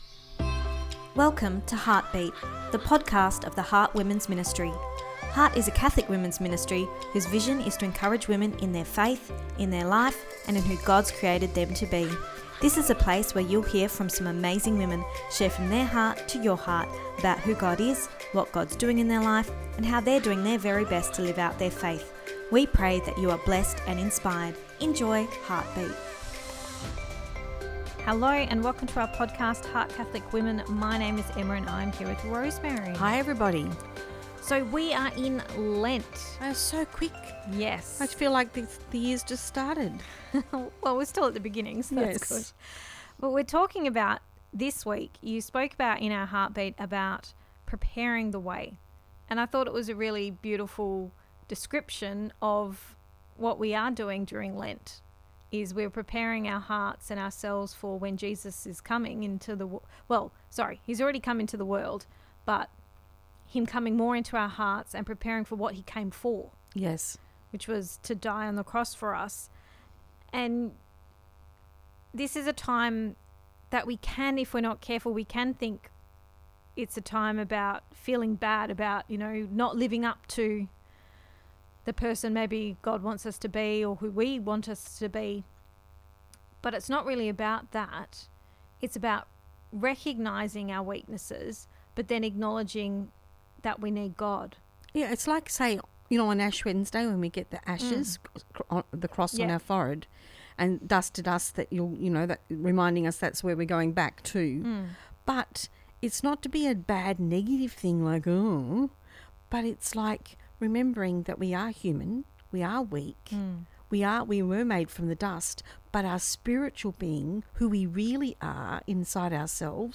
ep285-pt2-our-chat-lent-prepare-the-way.mp3